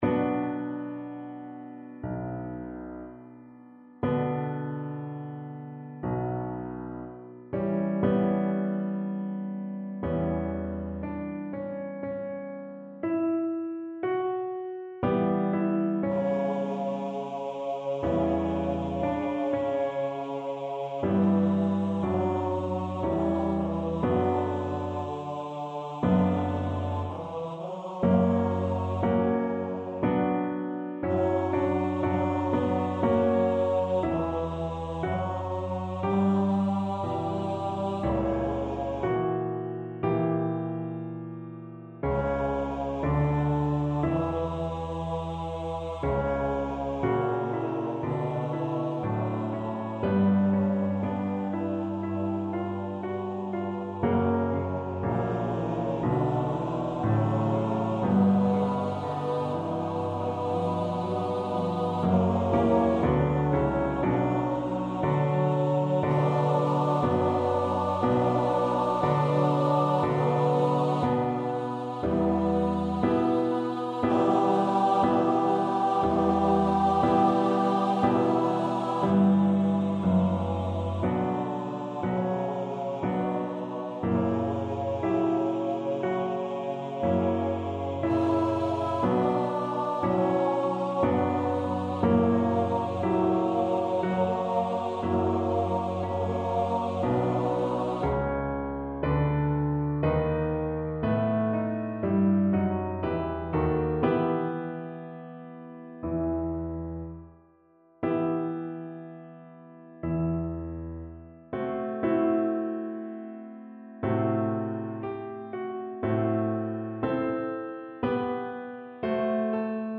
Instrument: Choir
Style: Classical